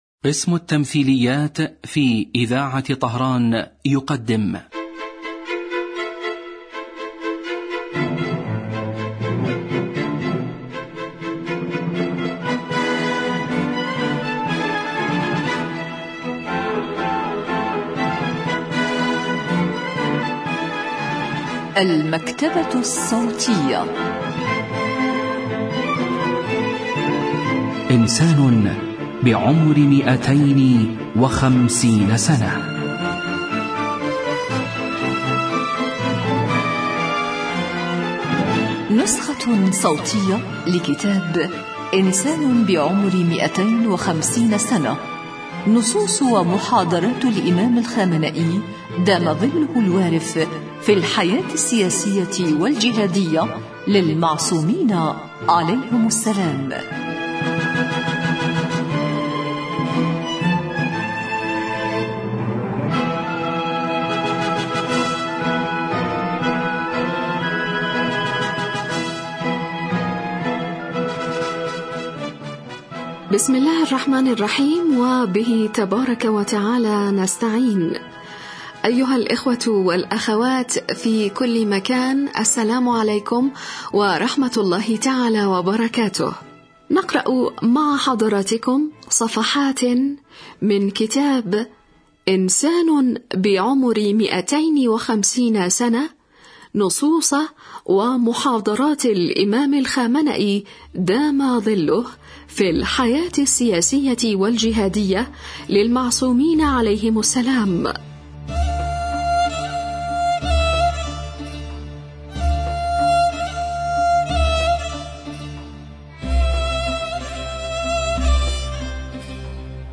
الكتاب الصوتي